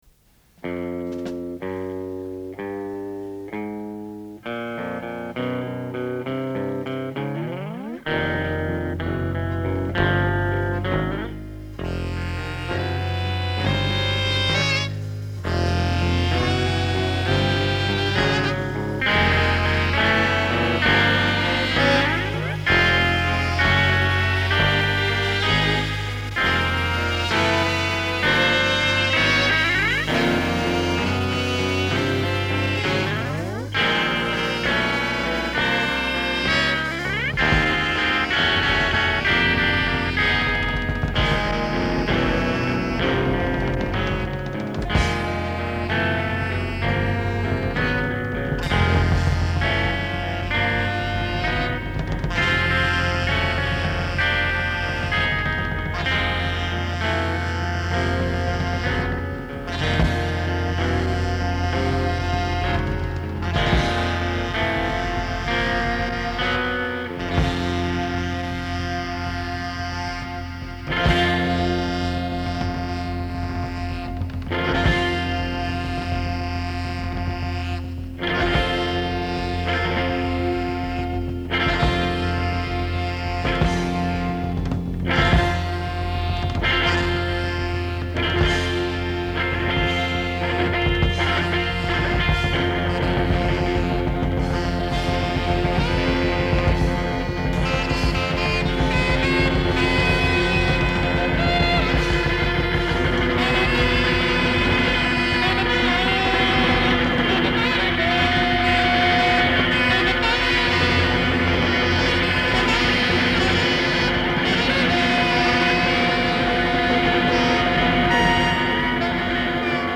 Live at White Columns, June 1981